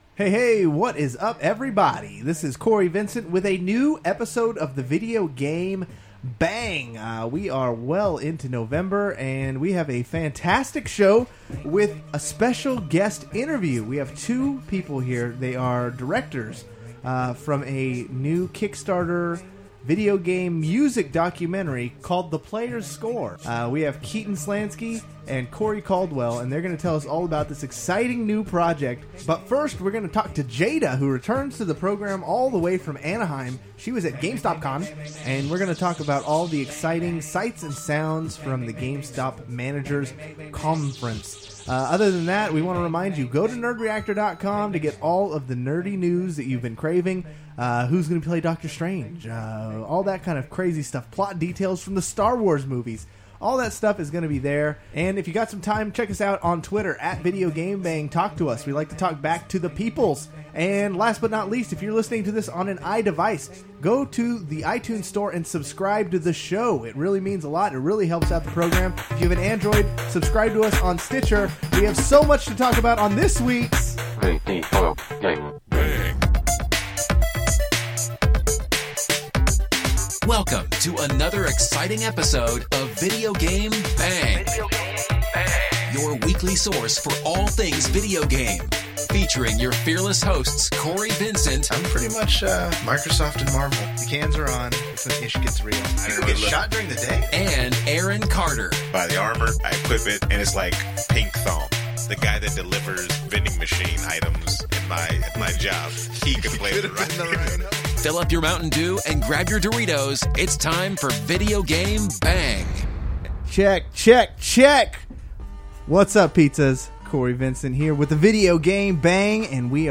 After a word from our sponsors.. err celebrity endorsers we have some special guests joining us live via the magic of Skype